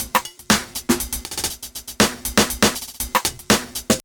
The new programmed fill with automation
… And in isolation.